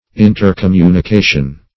Intercommunication \In`ter*com*mu`ni*ca"tion\, n.